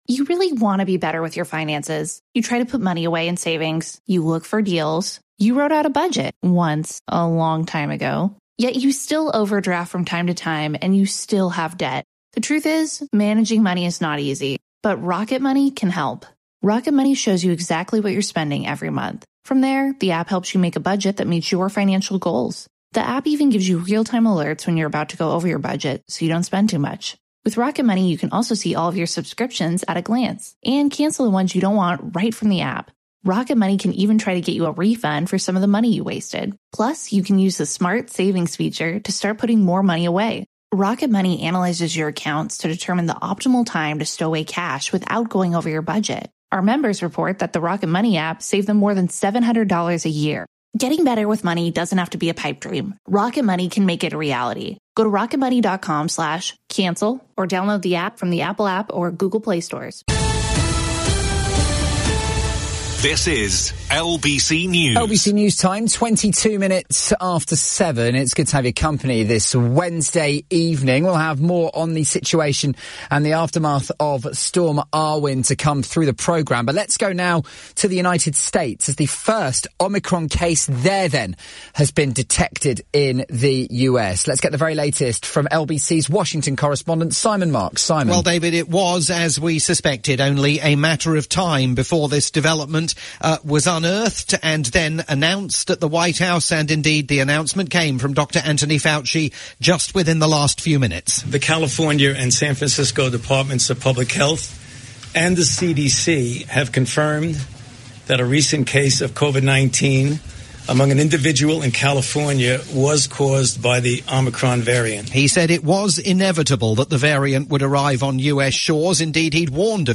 breaking news coverage